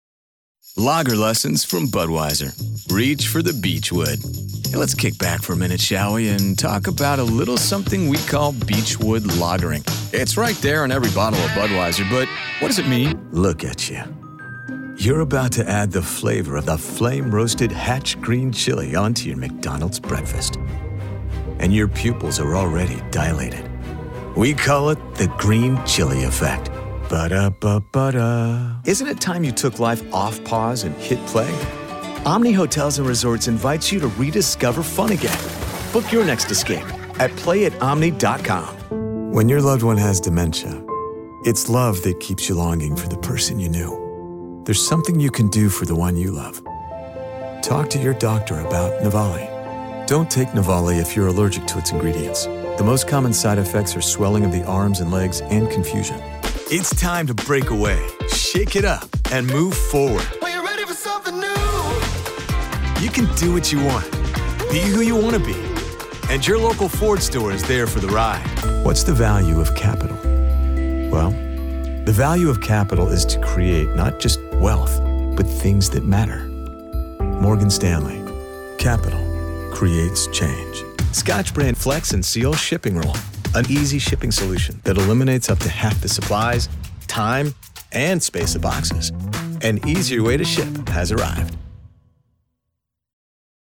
All of our contracted Talent have broadcast quality home recording studios.
Commercial Demo Video Demos McDonalds